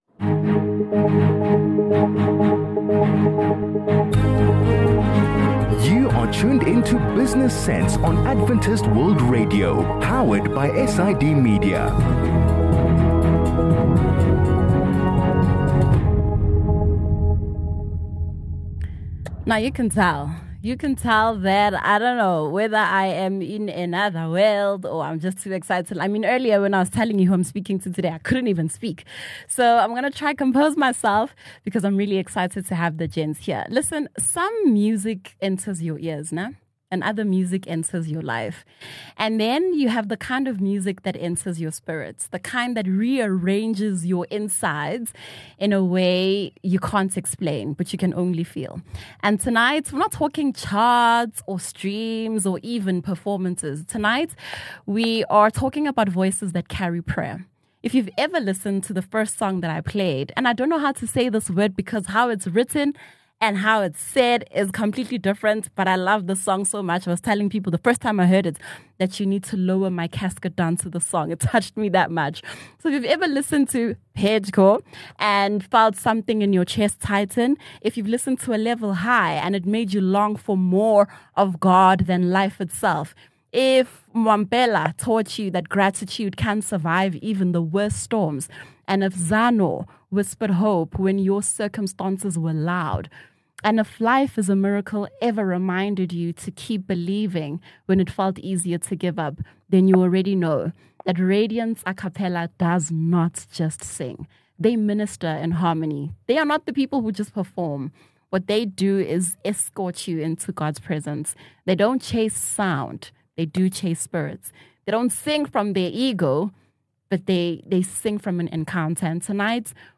Fresh off the highly anticipated launch of their new album, Freedom, the dynamic voices of Radiance Acappella join us for an exclusive, in-depth interview.